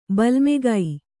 ♪ balmegai